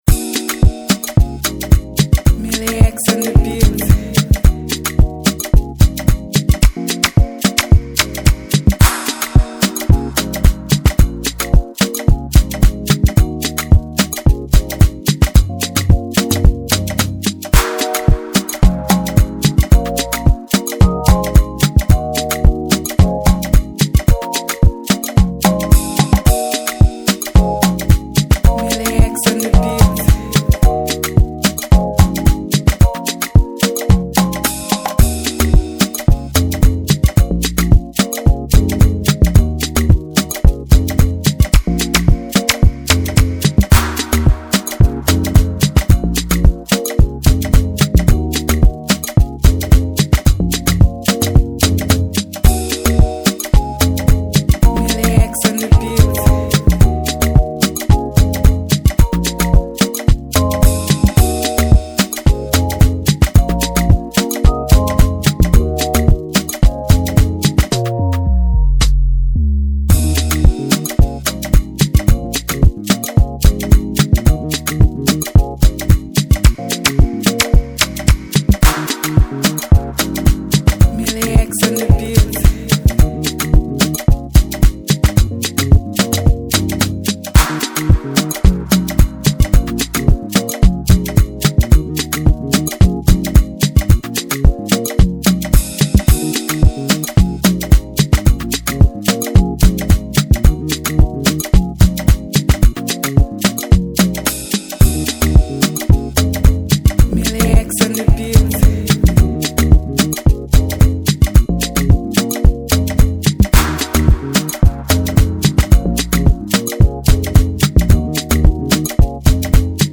FREEBEAT & INSTRUMENTAL
GENRE: Afro